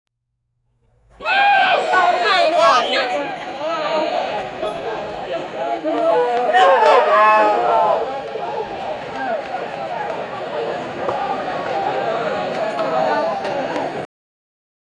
sound-of-paparazzi-camera-6ywphlq4.wav